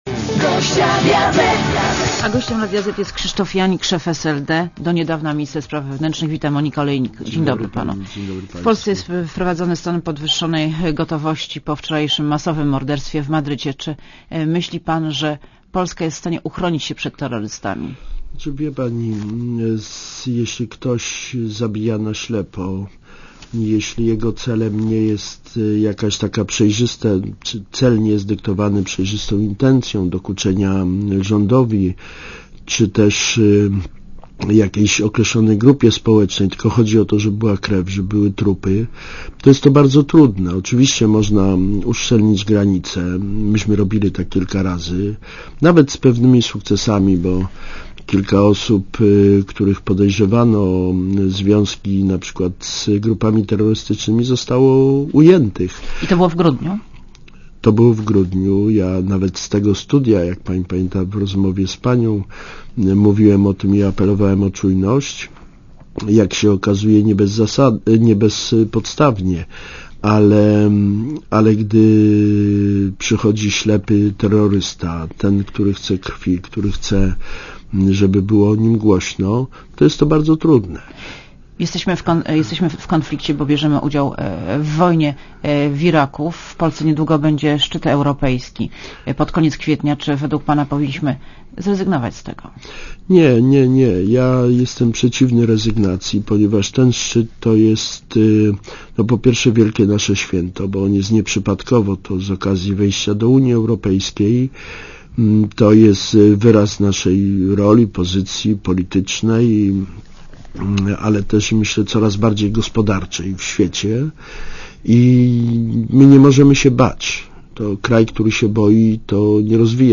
Posłuchaj wywiadu (2,58Mb) Gościem Radia Zet jest Krzysztof Janik, szef SLD, do niedawna minister spraw wewnętrznych i administracji.